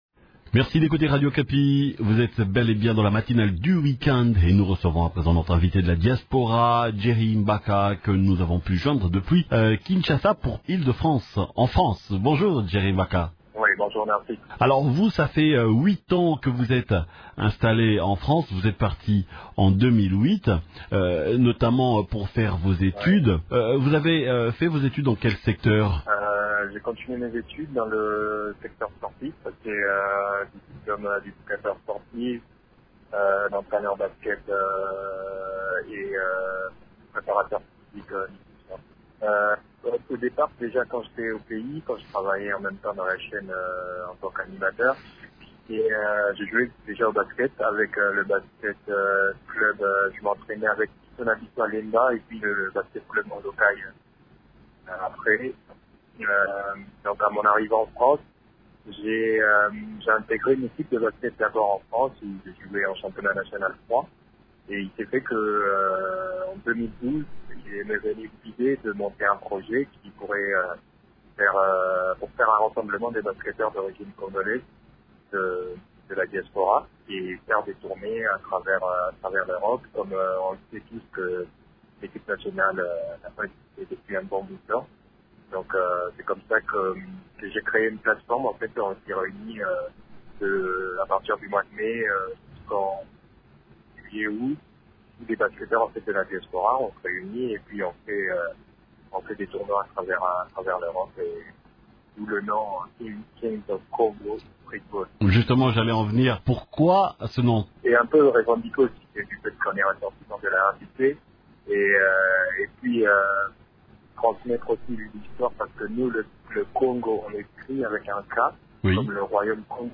s’entretien au téléphone